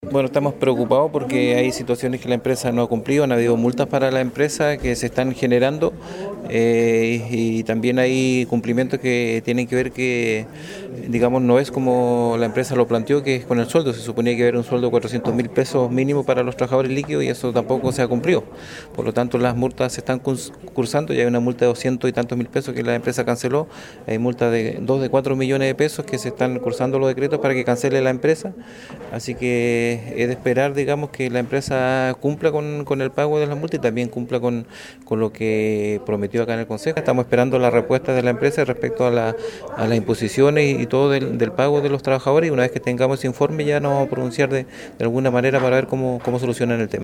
Dijo que desde el municipio se ha hecho el cálculo respectivo donde de manera preocupante, hay evidencias de que no se estaría cumpliendo con el pago de los 400 mil pesos que se habían prometido para los operarios de parquímetros, expresó el concejal Alex Muñoz.